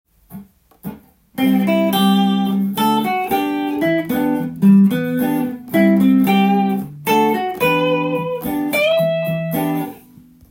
コードトーンを使いソロっぽく弾くとこのようになります。